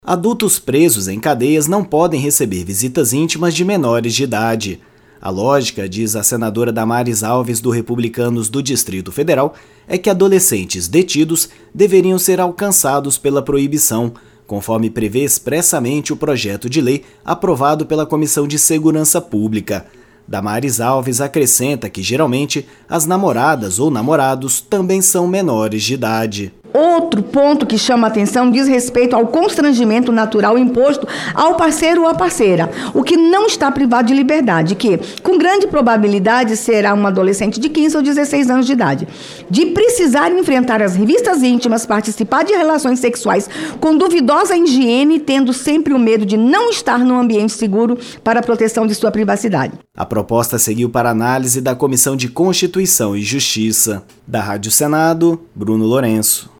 A relatora, Damares Alves (Republicanos-DF), diz que, se a lei proíbe visitas de menores a adultos presos, a restrição deve ser estendida a menores no sistema socioeducativo.